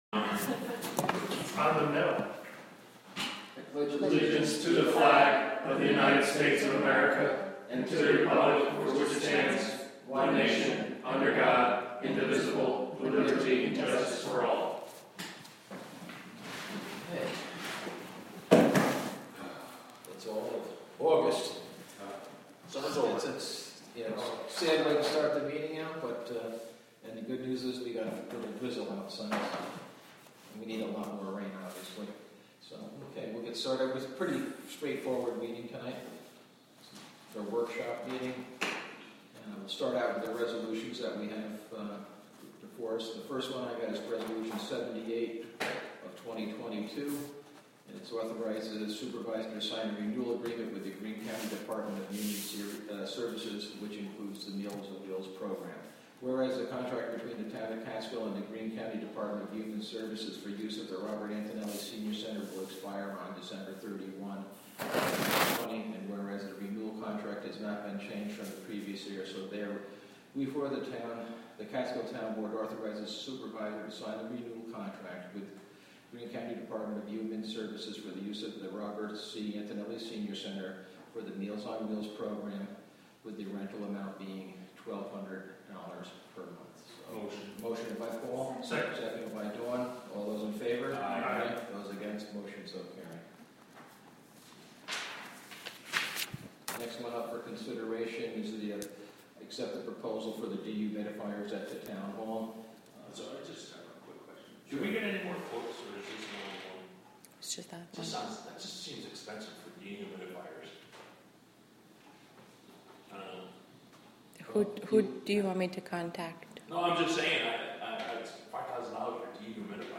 Live from the Town of Catskill: August 17, 2022 Town Board Meeting (Audio)